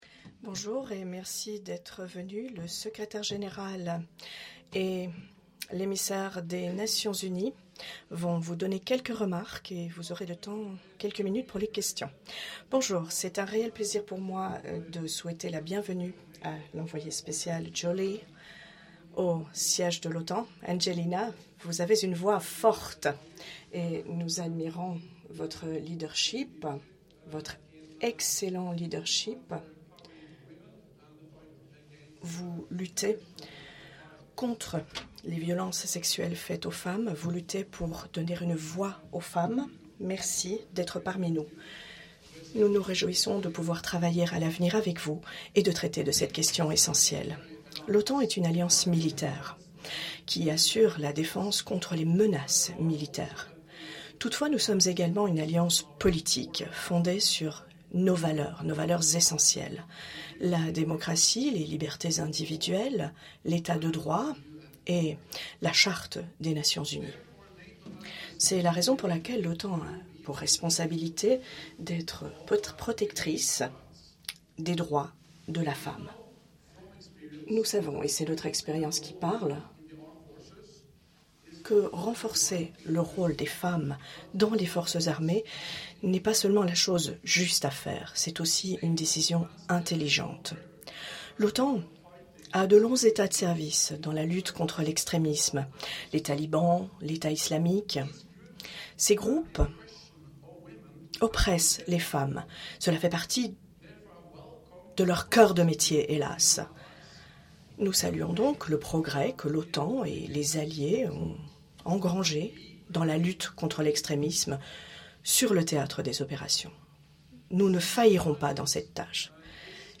Joint press conference by NATO Secretary General Jens Stoltenberg with Special Envoy for the United Nations High Commissioner for Refugees Angelina Jolie